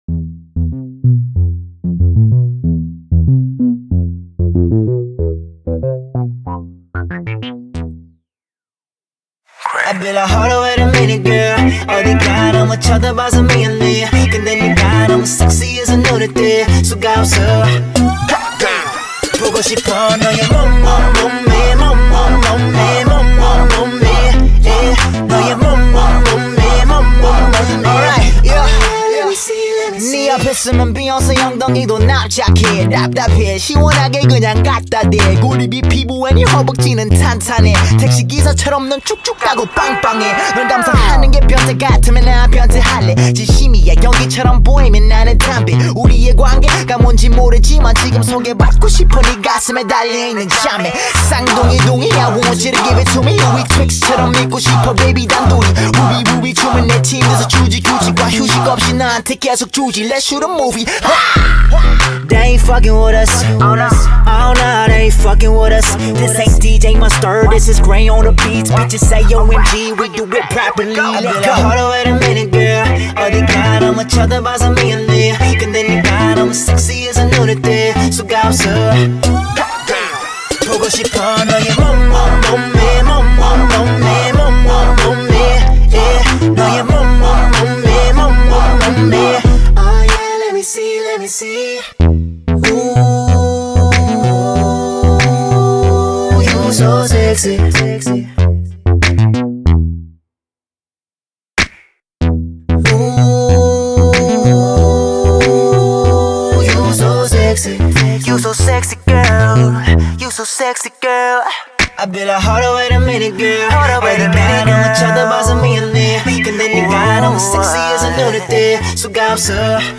BPM47-94
MP3 QualityMusic Cut